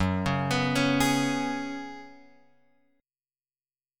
F#sus2sus4 chord {2 4 x 4 2 4} chord